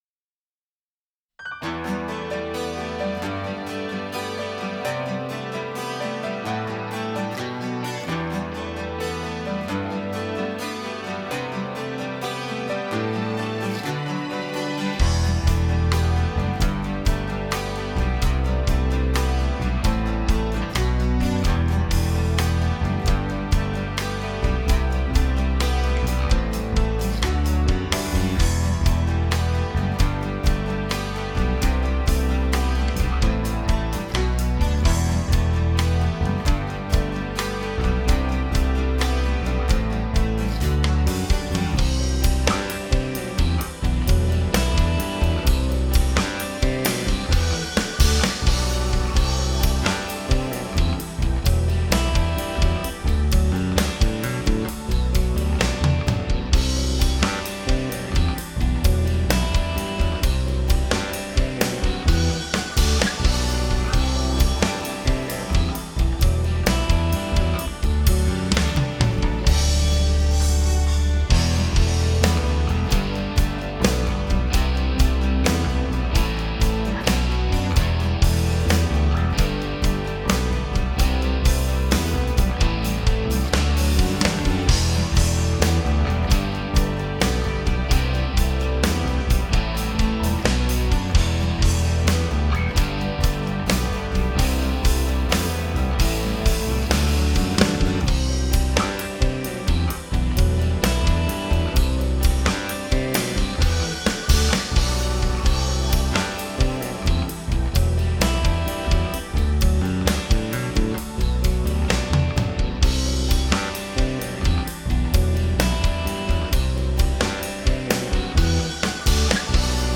Konkursowe podkłady muzyczne:
PODKŁAD 1,